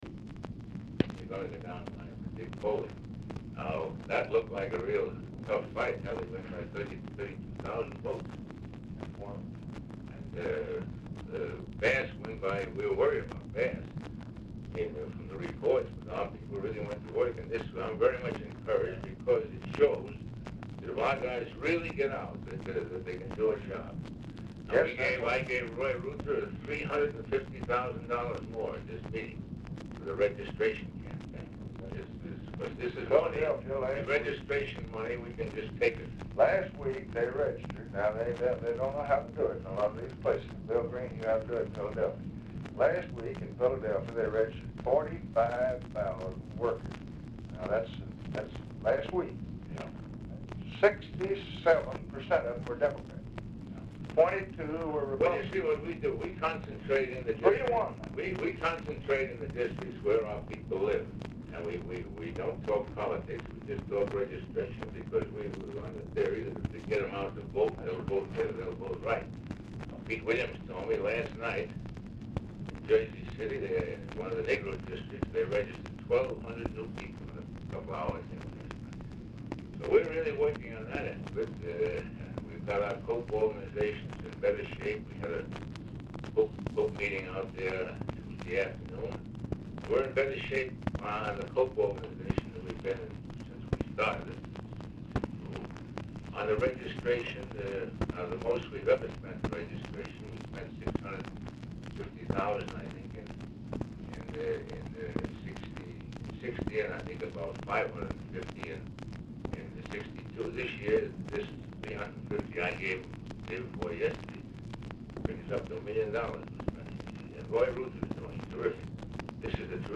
Telephone conversation
OFFICE CONVERSATION WHILE FELDMAN IS ON HOLD FOR LBJ
SPEAKERPHONE IS APPARENTLY CONNECTED
Format Dictation belt
OFFICE SECRETARY, OFFICE CONVERSATION